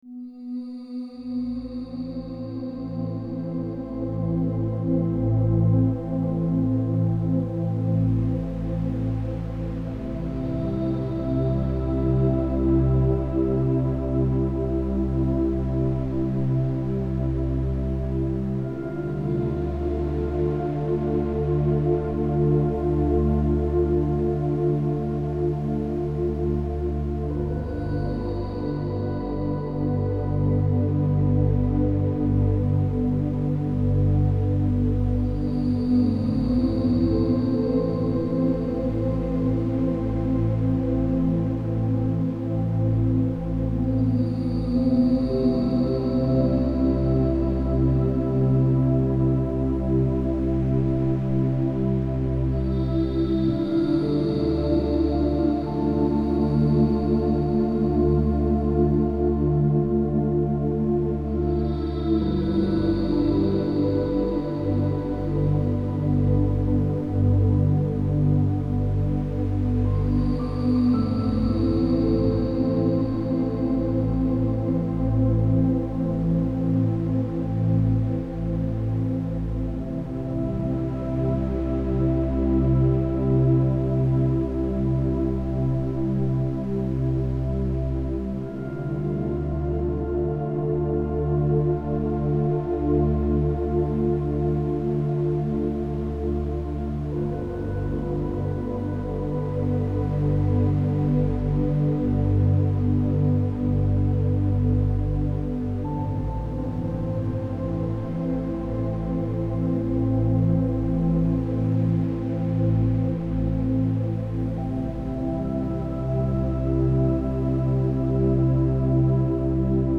это атмосферная композиция в жанре неоклассики